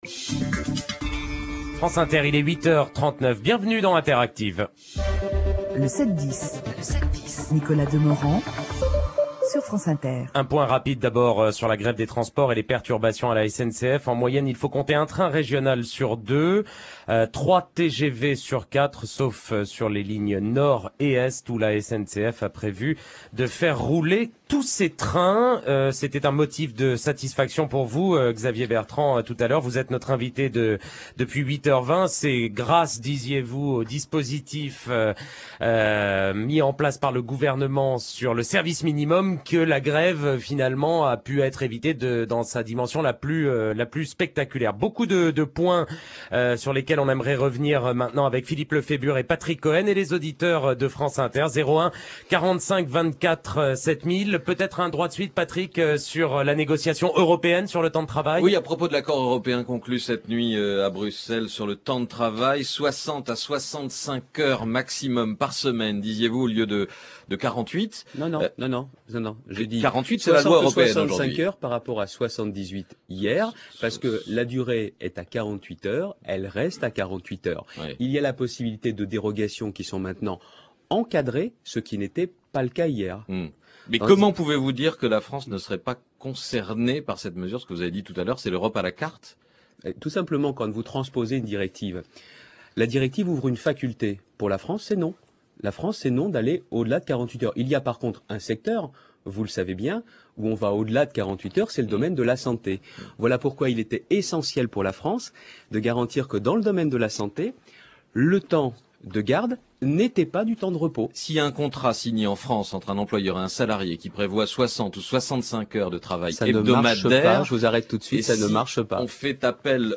Quelques exemples, extraits de ses interventions dans le 7/10 de France Inter du 10 Juin : Relations difficiles entre gouvernement et syndicats.